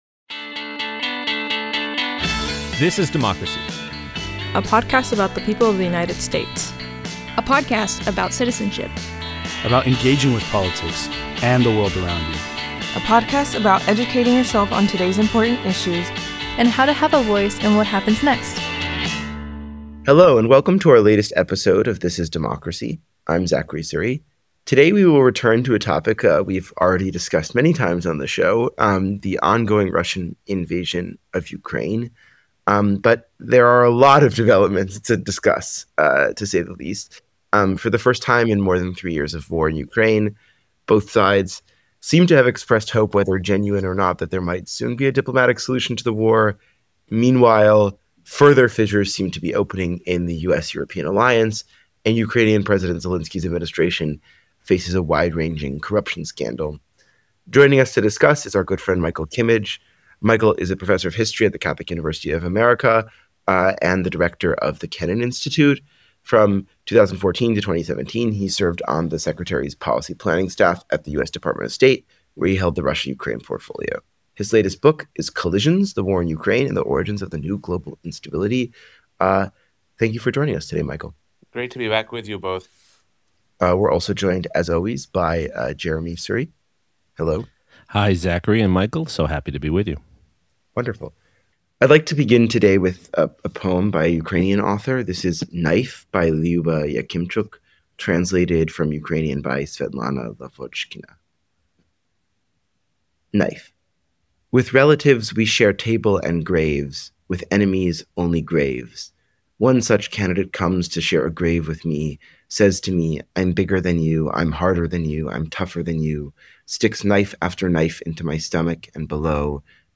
Dr. Jeremi Suri, a renown scholar of democracy, will host the podcast and moderate discussions.